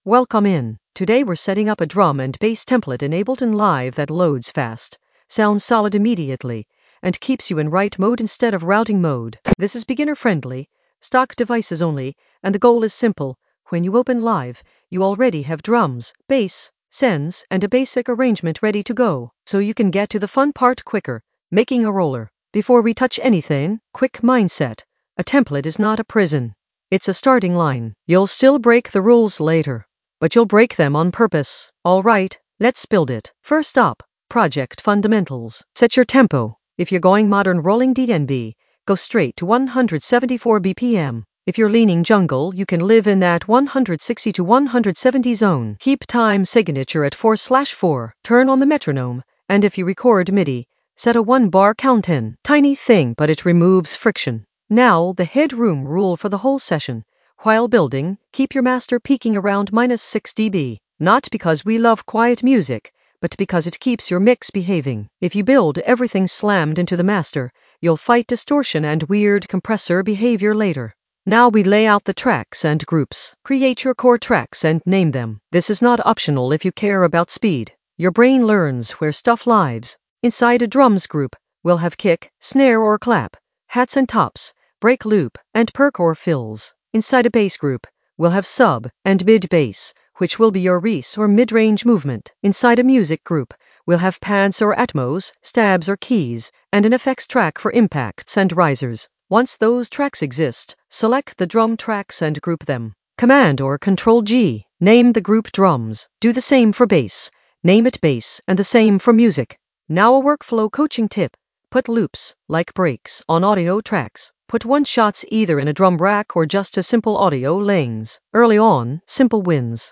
An AI-generated beginner Ableton lesson focused on Template setup for DnB with simple racks in the Workflow area of drum and bass production.
Narrated lesson audio
The voice track includes the tutorial plus extra teacher commentary.